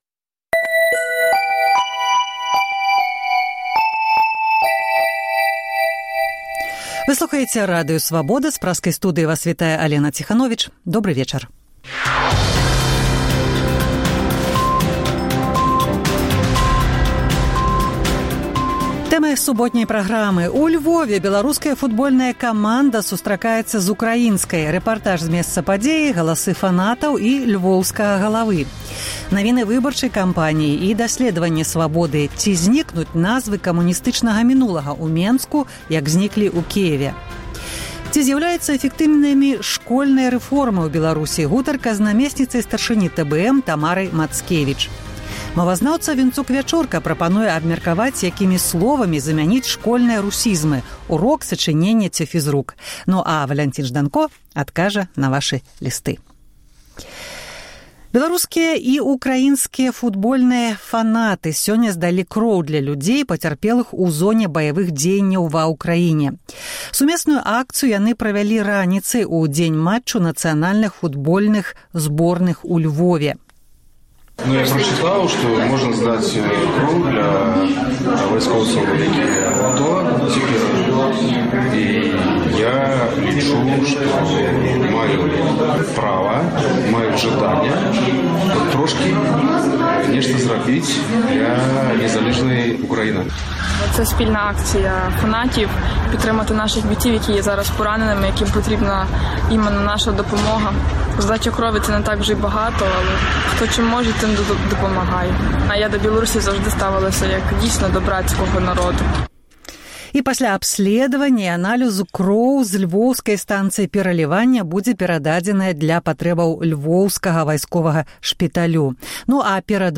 У Львове беларуская футбольная каманда сустракаецца з украінскай. Рэпартаж зь месца падзеі, галасы фанатаў і львоўскага галавы. Дасьледаваньне Свабоды: ці зьнікнуць назвы камуністычнага мінулага ў Менску, як зьніклі ў Кіеве.